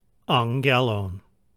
Hear It> (a plural form of ἄɣɣελος):
Pronunciation Note: When there is a double Gamma (ɣɣ) in a word, such as in ἄɣɣελος, the first Gamma is pronounced like the ng in sing.